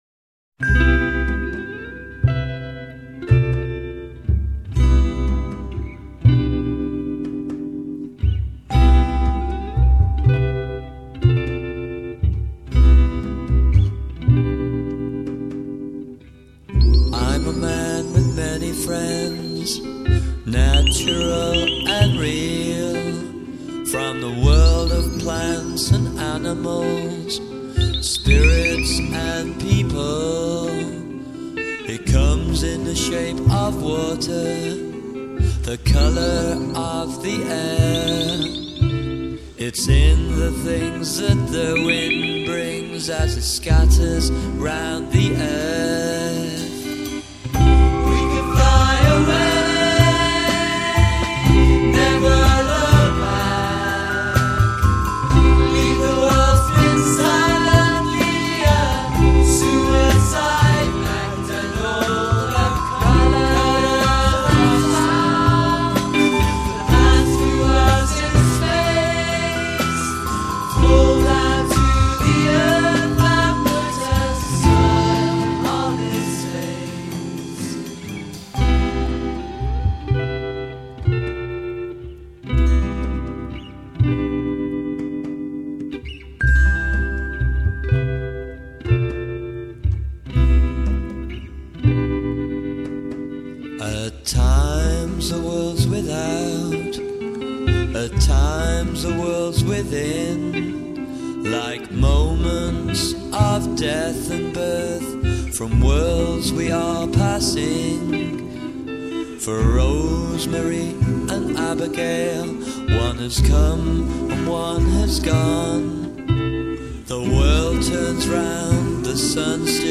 recorded in la banciere